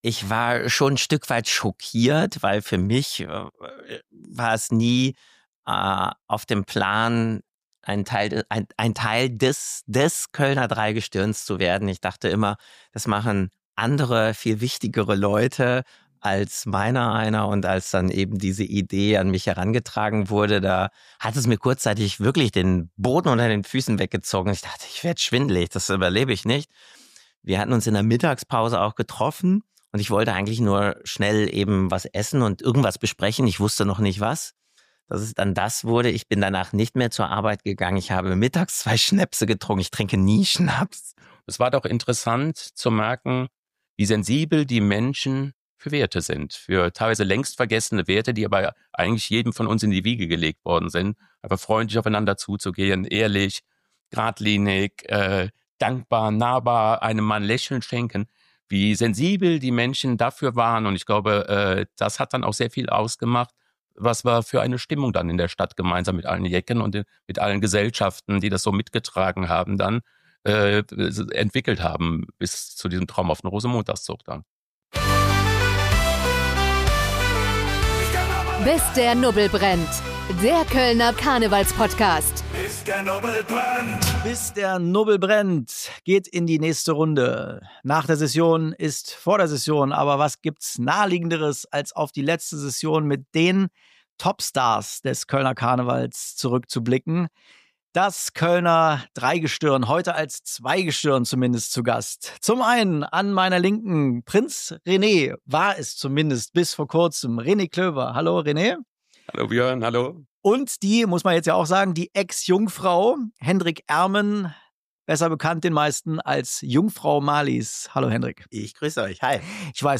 Voller Geschichten, Lachen, Gänsehaut und dem einen oder anderen stillen Moment der Rührung.